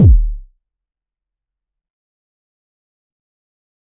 Crisp start with short attack. a bit of sustain with distortion. 0:04 Created May 6, 2025 6:39 PM Clean Kick one shot, 909. a bit of grid and noise in the higher frequencies. Crisp start with diffuse feedback end 0:04 Created May 6, 2025 6:35 PM
clean-kick-one-shot-909-qy6gn3kl.wav